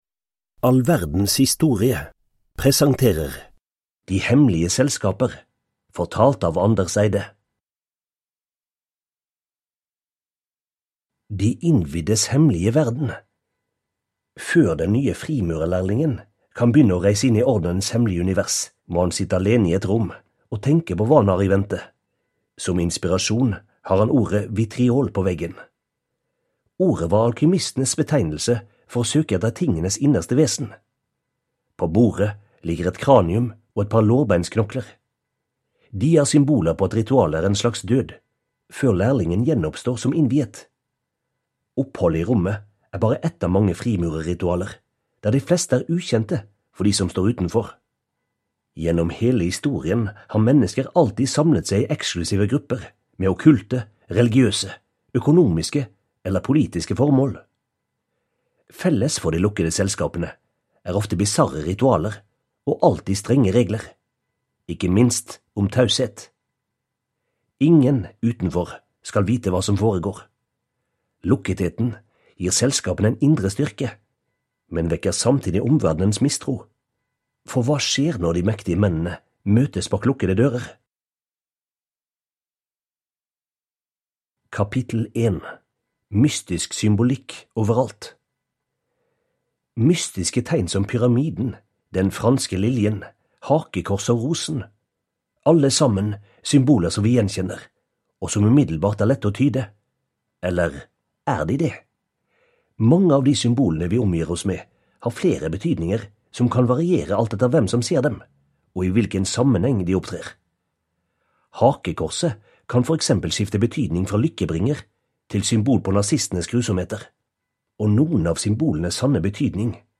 Hemmelige selskaper (ljudbok) av All verdens historie